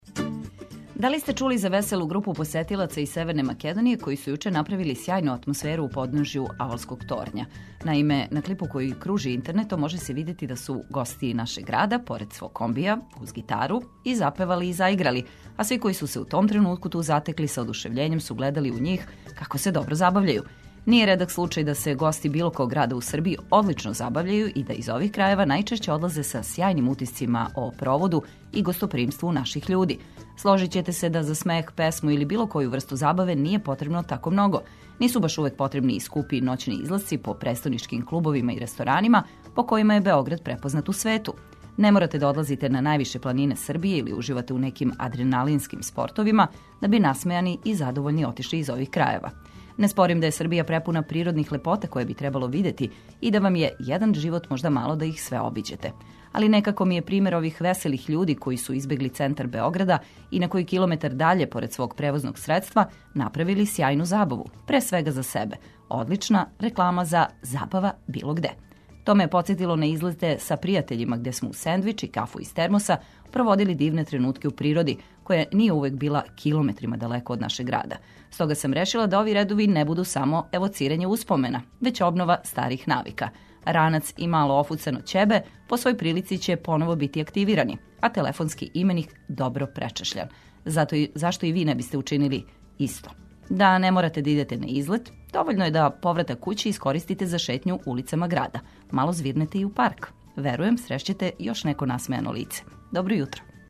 Све што треба да знате сазнајте од нас, слушајући добру музику.